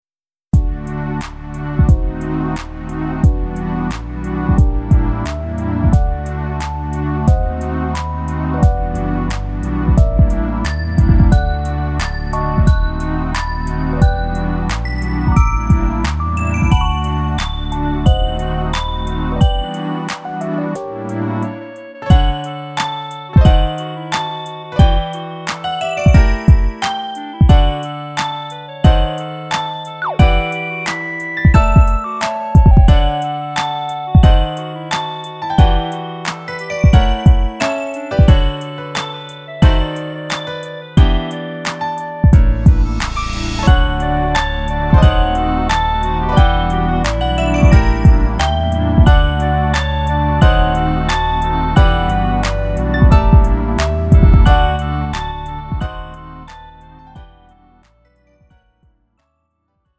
음정 원키 3:23
장르 가요 구분 Lite MR
Lite MR은 저렴한 가격에 간단한 연습이나 취미용으로 활용할 수 있는 가벼운 반주입니다.